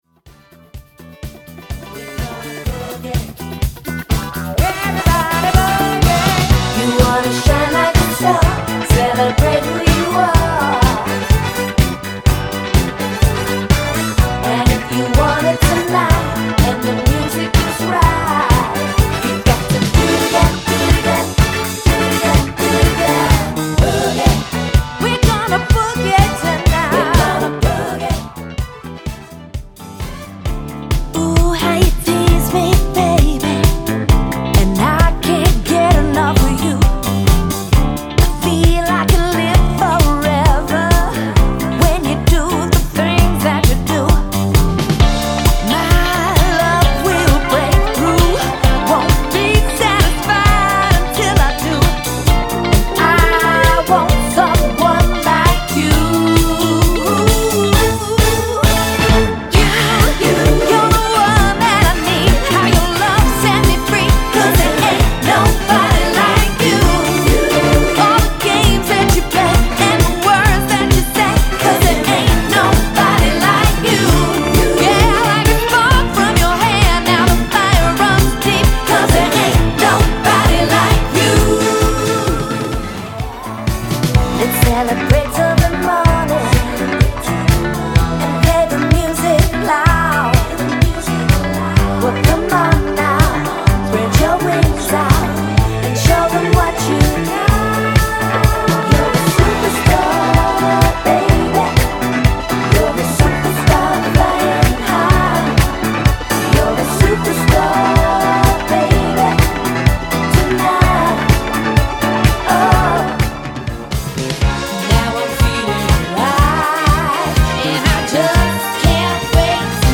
on a Disco album project.